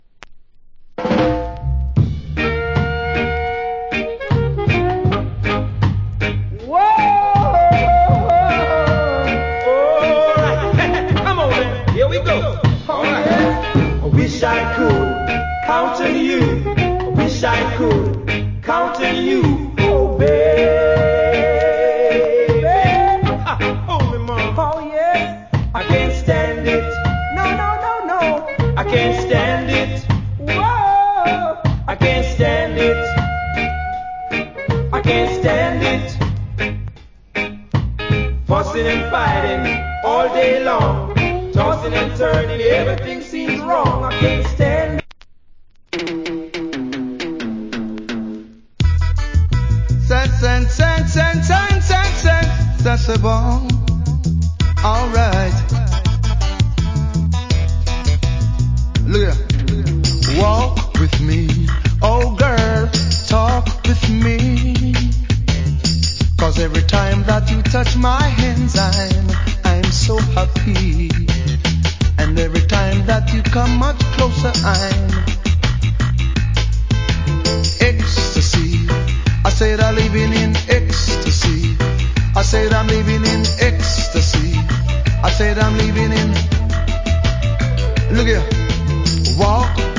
コメント Wicked Rock Steady Vocal.
/ Good Reggae Vocal.